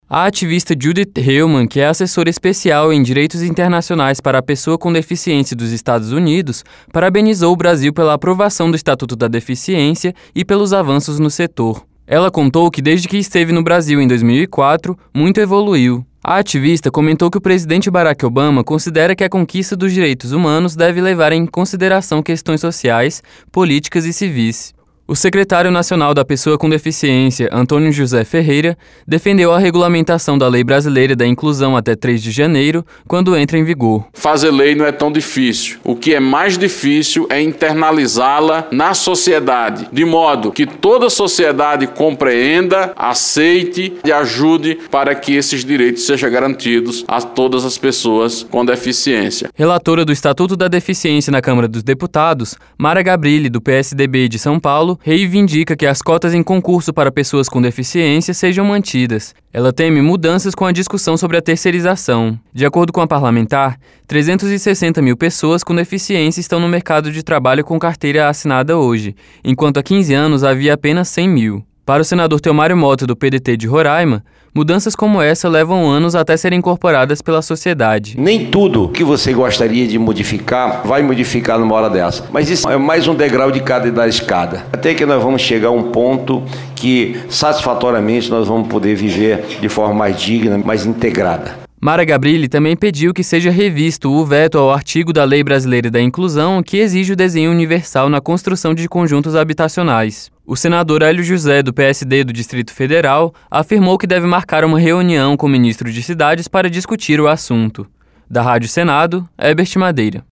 CDH debate direitos dos deficientes no Brasil e nos EUA — Rádio Senado